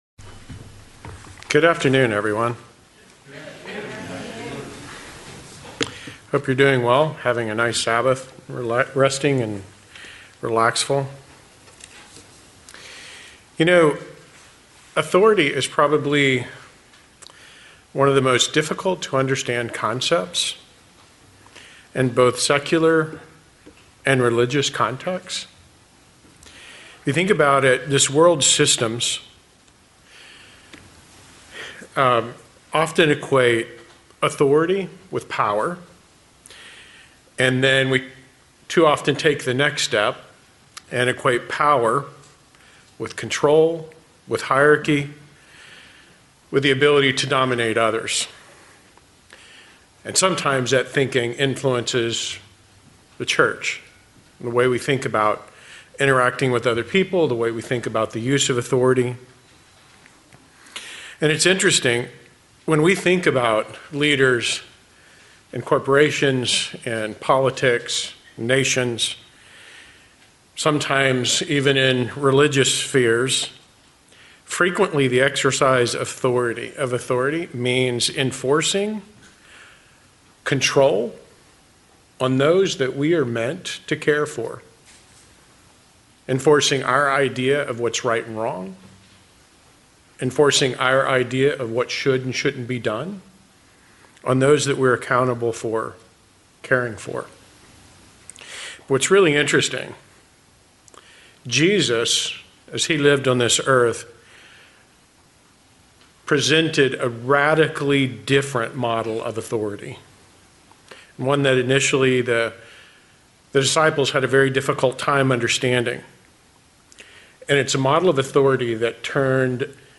Sermons
Given in Las Vegas, NV